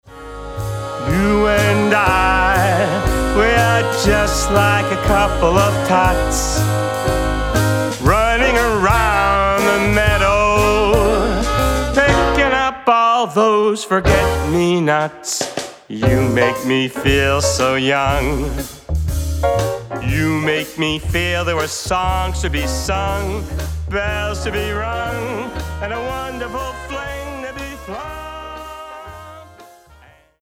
Vocalist